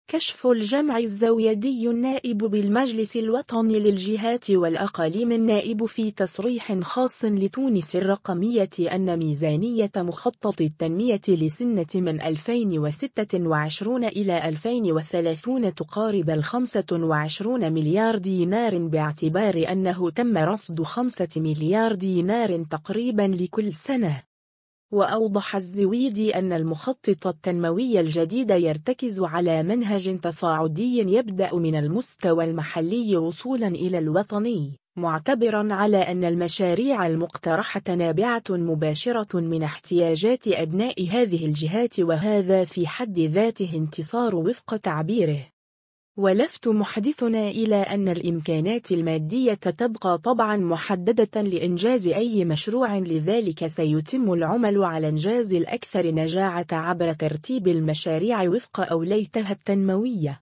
كشف الجمعي الزويدي النائب بالمجلس الوطني للجهات والأقاليم النائب في تصريح خاص لـ”تونس الرقمية” أن ميزانية مخطط التنمية لسنة 2026-2030 تُقارب الـ25 مليار دينار باعتبار انه تم رصد 5 مليار دينار تقريبا لكل سنة.